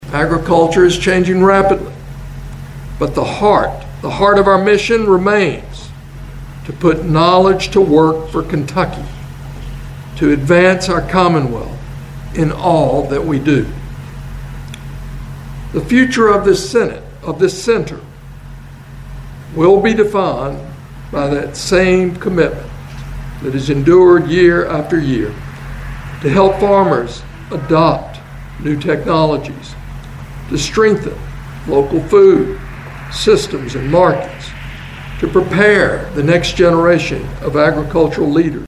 The University of Kentucky leadership and board of trustees were joined by farmers, state and local officials, and community members to celebrate a century of service at the Research and Education Center in Princeton.